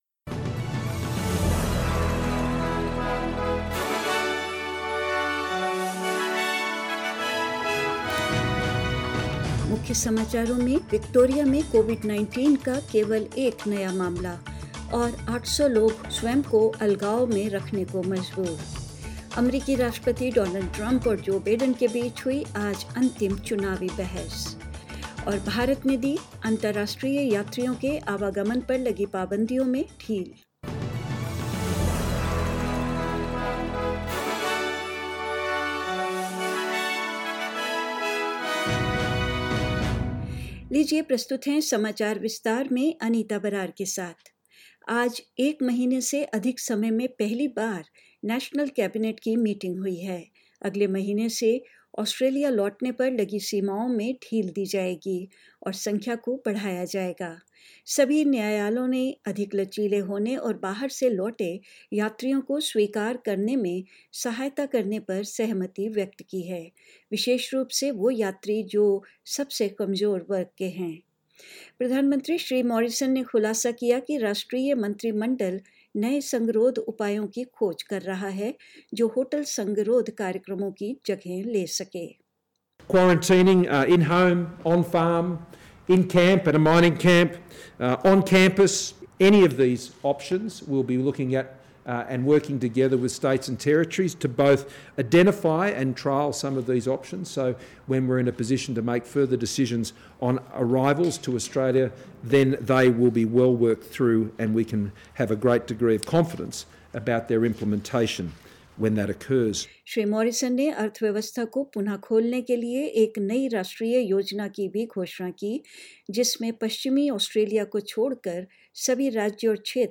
News in Hindi 23rd October 2020